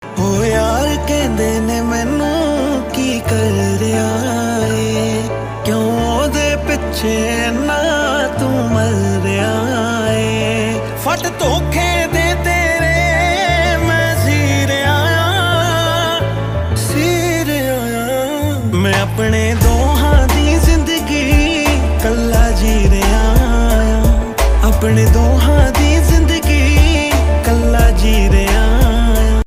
new punjabi ringtone